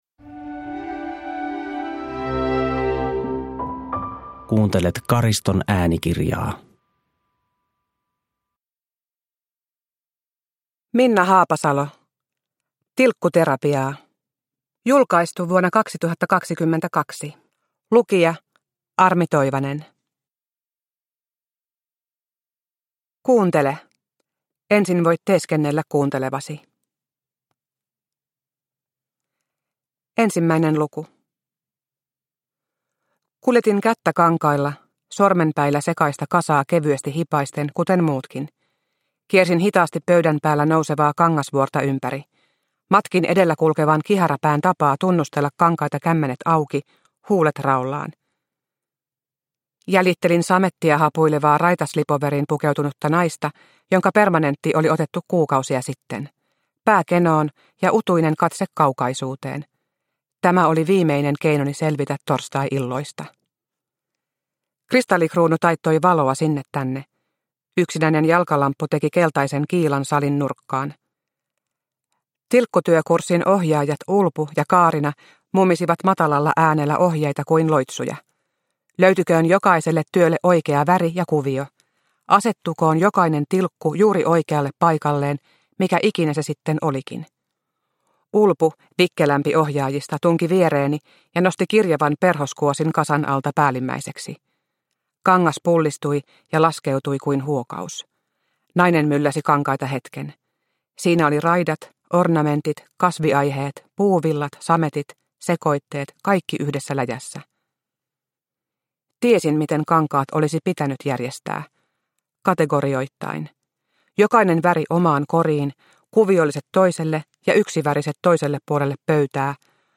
Tilkkuterapiaa – Ljudbok – Laddas ner
Uppläsare: Armi Toivanen